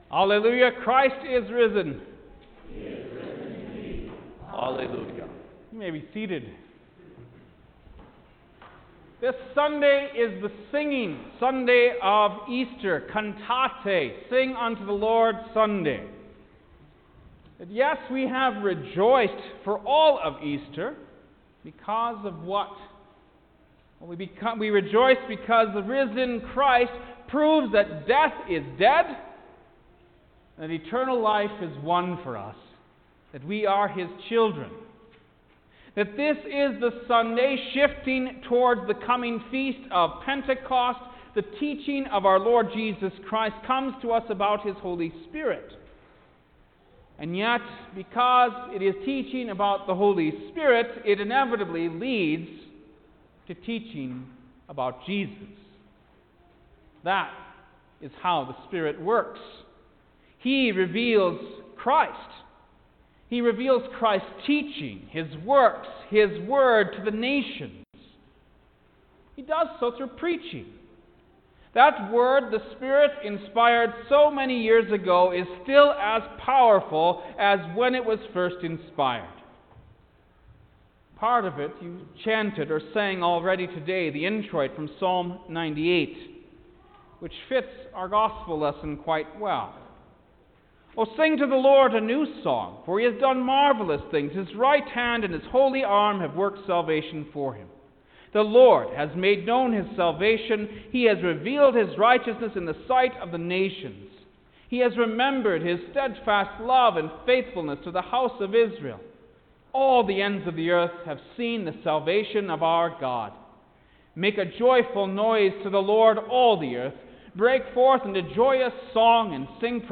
May-2_2021-Fifth-Sunday-of-Easter_Sermon-Stereo.mp3